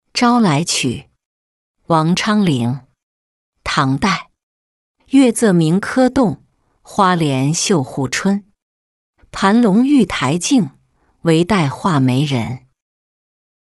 朝来曲-音频朗读